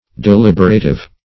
Deliberative \De*lib"er*a*tive\, n.